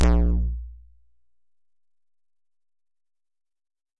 描述：这是个电子贝司样本。它是用Sonic Charge的电子VST工具Micro Tonic制作的。
在归一化之后，它被抖动为16比特，并转换为flac格式以节省空间。
标签： 电子
声道立体声